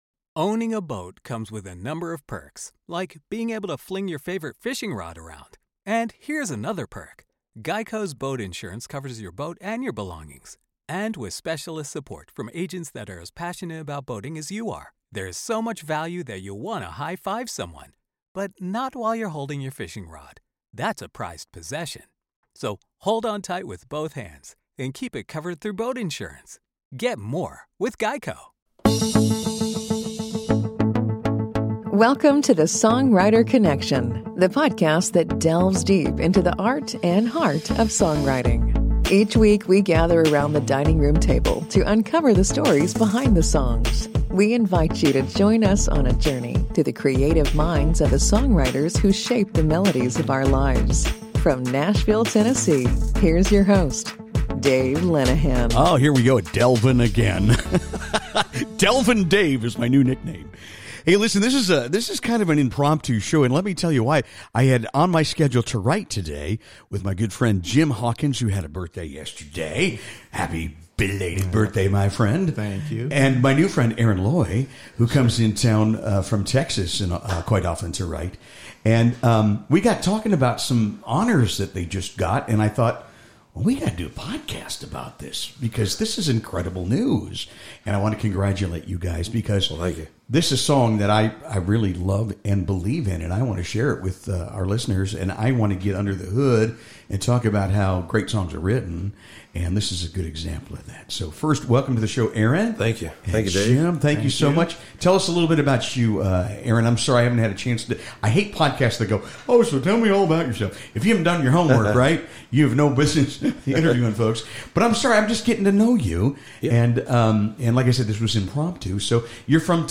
Gather around the dining room table with us as they delve into the inspiration behind their award-winning track, share exclusive performances, and exchange heartfelt stories from their songwriting journey. Whether you're a seasoned songwriter or a music lover, you won’t want to miss this intimate conversation filled with passion, creativity, and a love for storytelling through song.